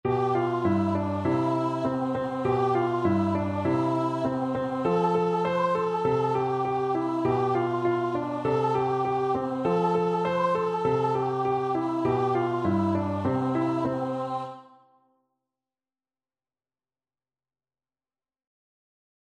World Europe Switzerland
2/4 (View more 2/4 Music)
Traditional (View more Traditional Voice Music)